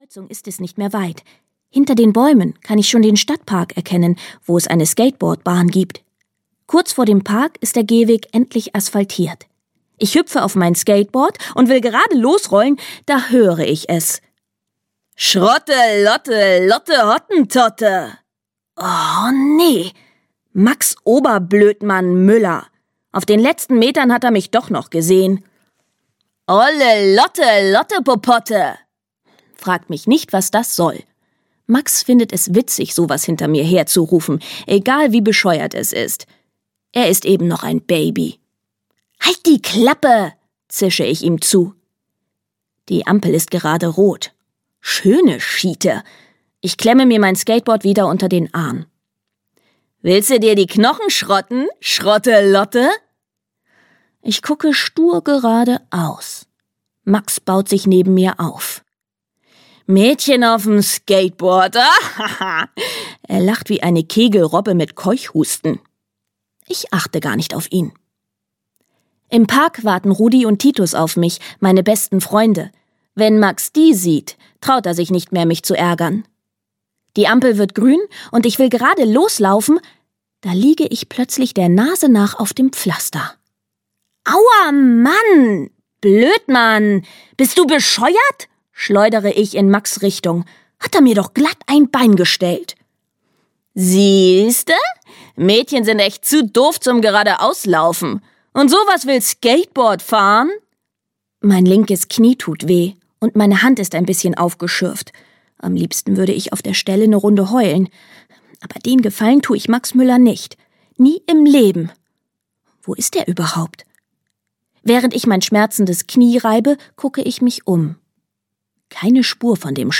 Das einzig coole Pferd, die Killerenten und ich - Dagmar Hoßfeld - Hörbuch